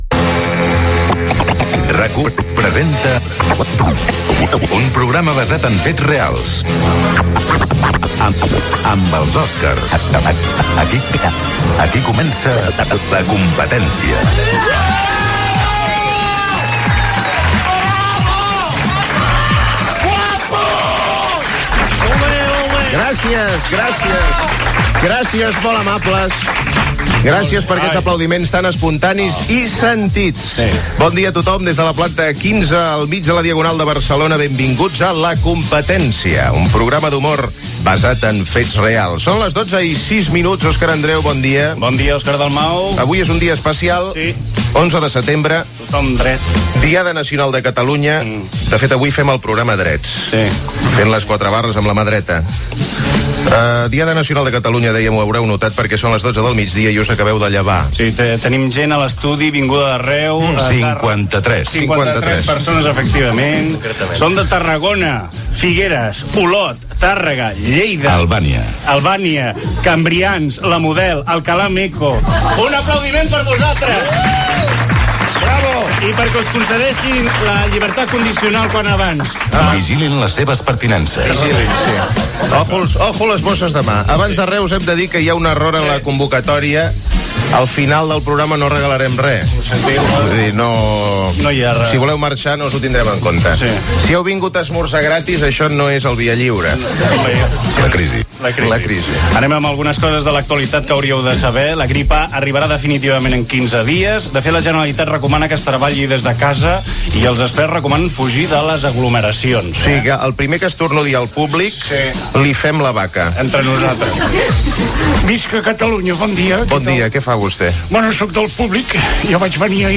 Careta del programa, la Diada Nacional de Catalunya, el públic que està a l'estudi, repàs a l'actualitat, indicatiu, els discs de l'Himne dels Segadors, titulars amb Justo Molinero (imitació), concurs
Entreteniment